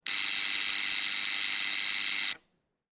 Door Buzzer ringtone free download
Sound Effects